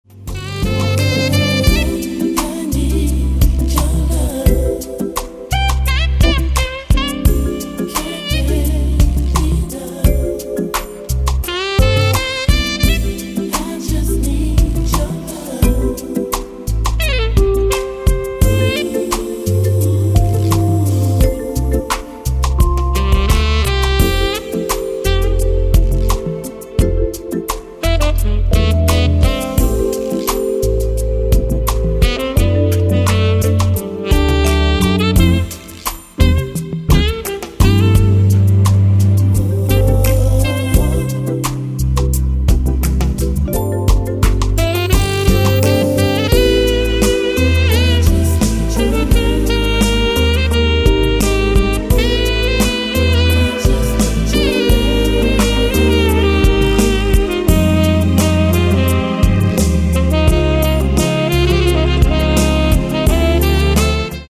Tenor-Saxophon